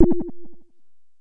bleep
alert beep bleep ding percussion ping soft sonar sound effect free sound royalty free Sound Effects